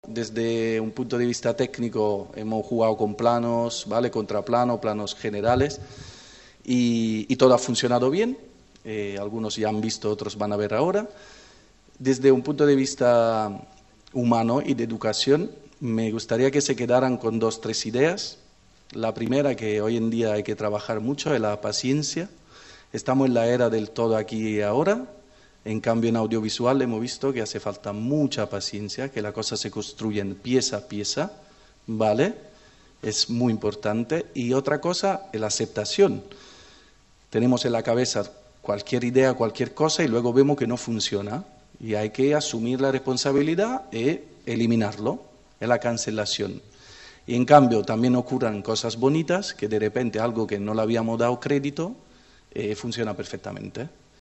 Raquel Sans, regidora de Joventut